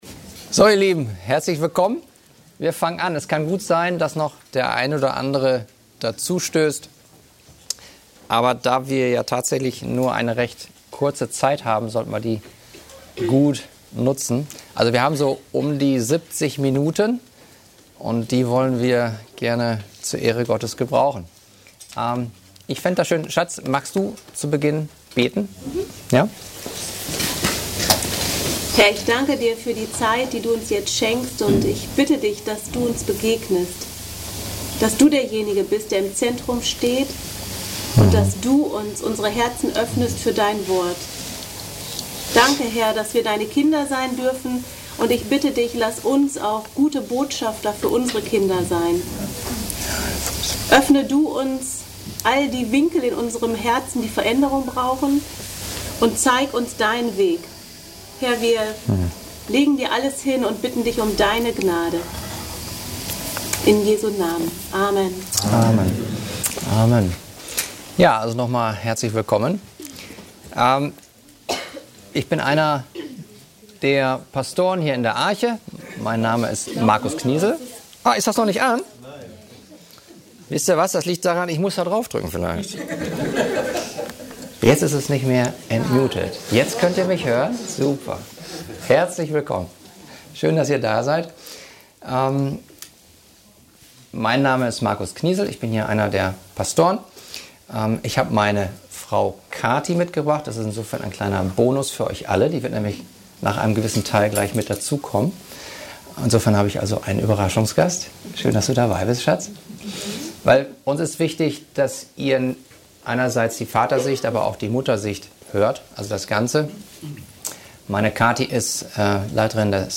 Missionsfeld Familie – Eltern als Botschafter Christi (Eckstein 2024 – Seminar 7) | 2.